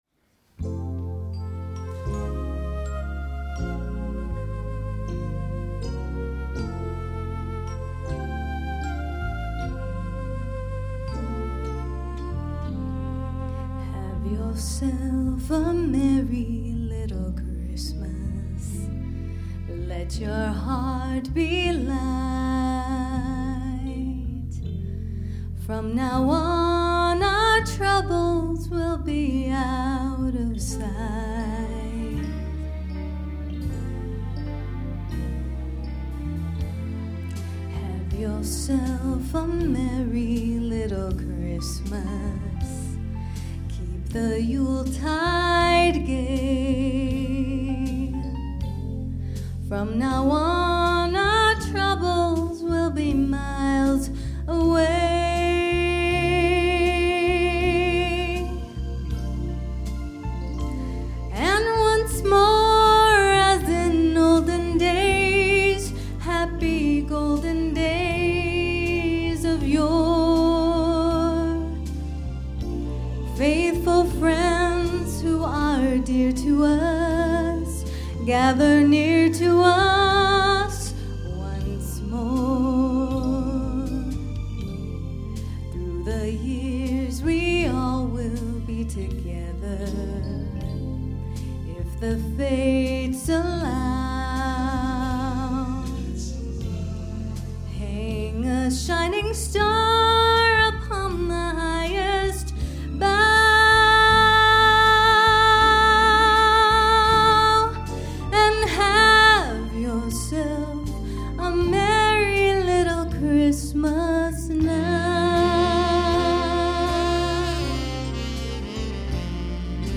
Center for Spiritual Living, Fremont, CA
2009 Winter Concert, Wednesday, December 16, 2009